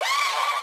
wooo.wav